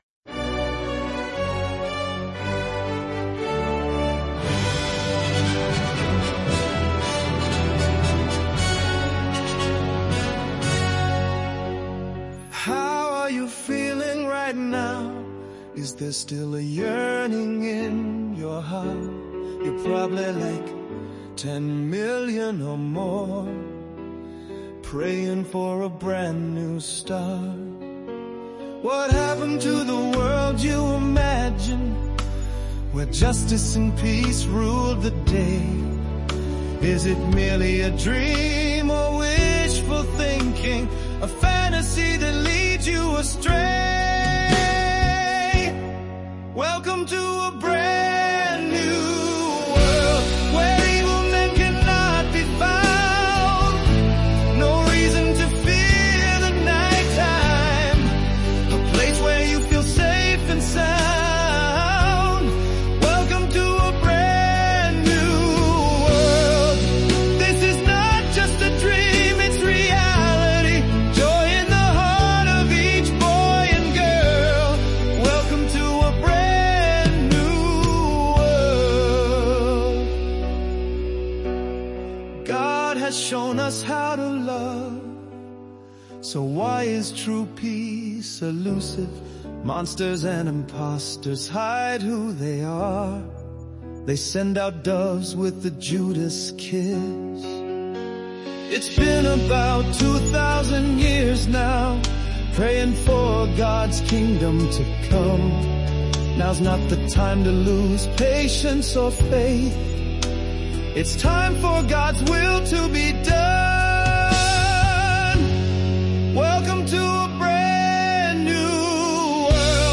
New song from old lyrics professionally recorded, hope you like it!
Re-posted with music and vocals
I paid for a producer who did the music and voice, I couldn't tell you who the singer is but he is excellent.